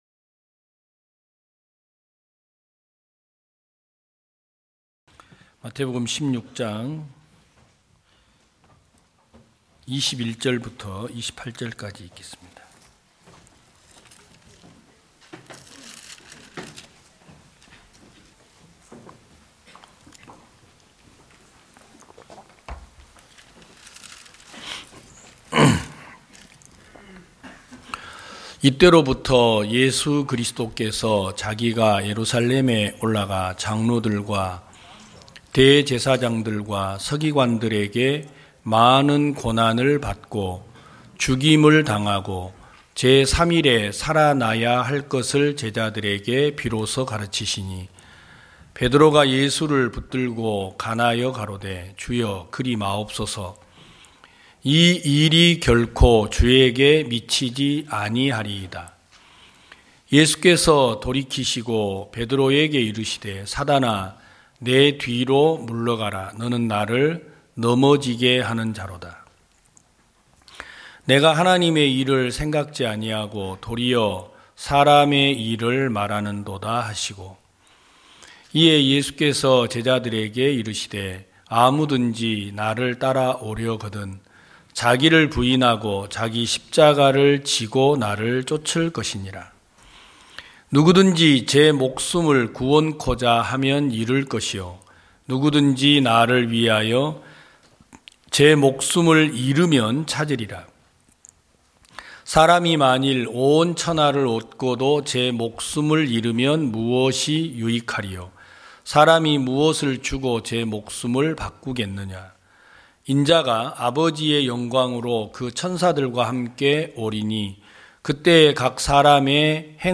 2022년 12월 18일 기쁜소식부산대연교회 주일오전예배
성도들이 모두 교회에 모여 말씀을 듣는 주일 예배의 설교는, 한 주간 우리 마음을 채웠던 생각을 내려두고 하나님의 말씀으로 가득 채우는 시간입니다.